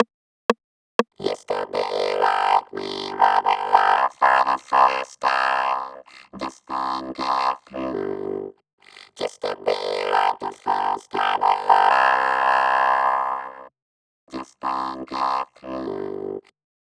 20 - Just to be in Love (121 BPM)
Chorus VOCODER FX.wav